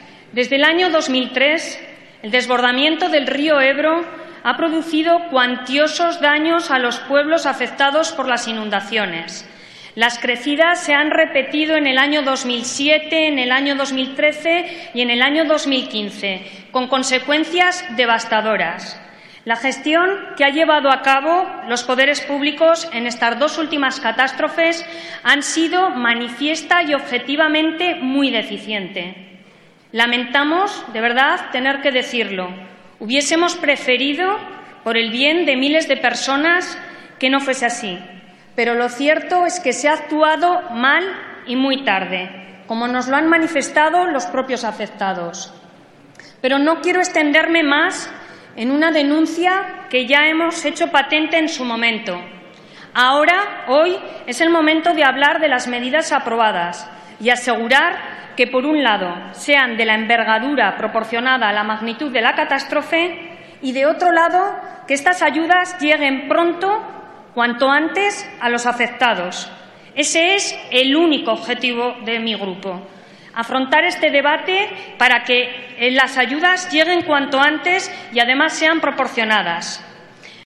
Fragmento de la intervención de Susana Sumelzo en el debate en el Pleno sobre el decreto de ayudas a los perjudicados por la crecida del Ebro